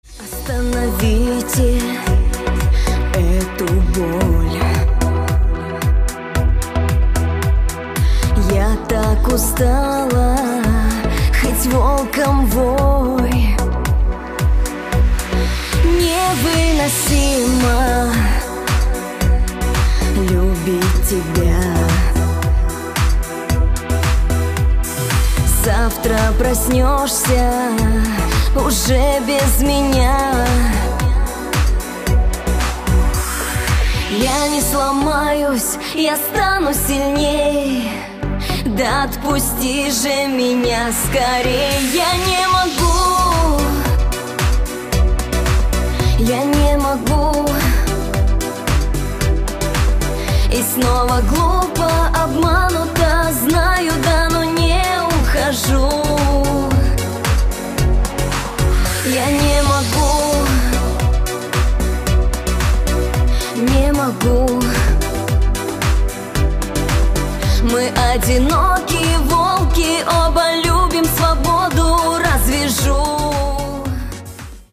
• Качество: 224, Stereo
грустные
спокойные
русский шансон
красивый женский голос